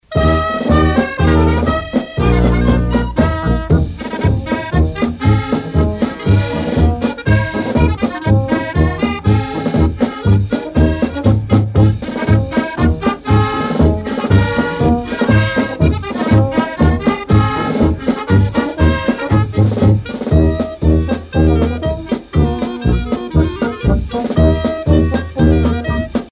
danse : marche